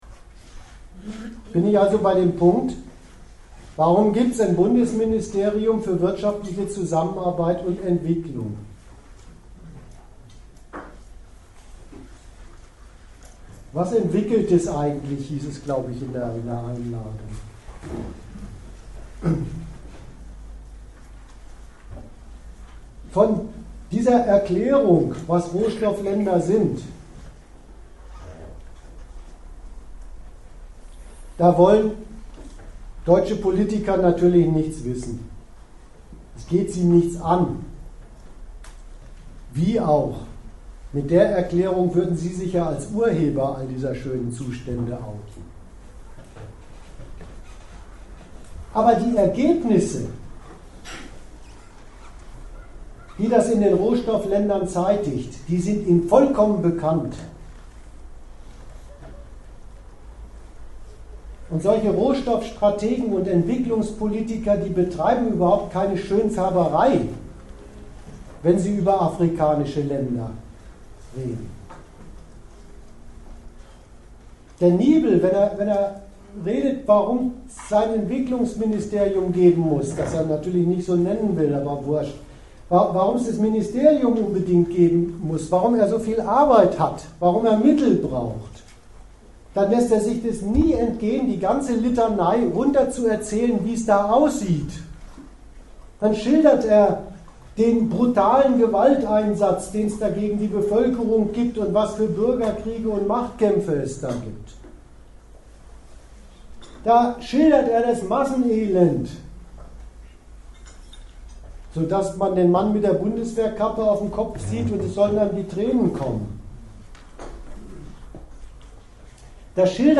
Ort Bremen Themenbereich Staatenkonkurrenz und Imperialismus
Dozent Gastreferenten der Zeitschrift GegenStandpunkt